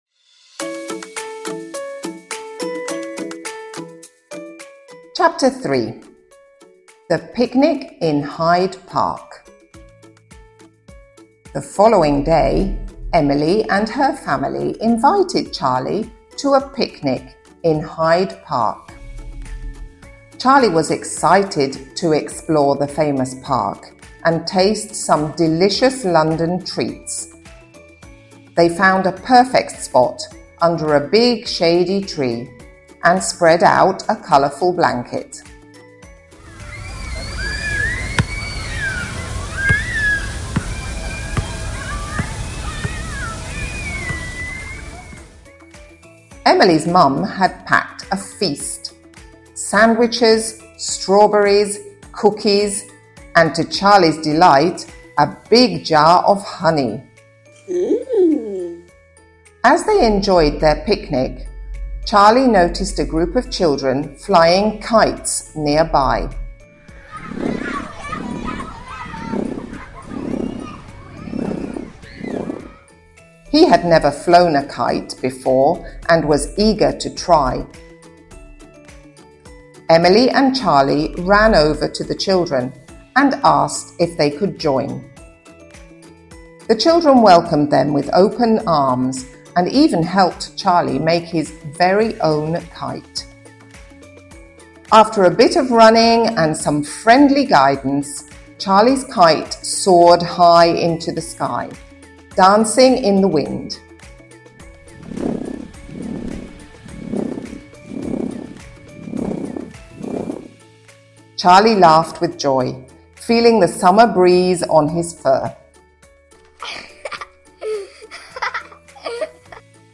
sound effects from Pixabay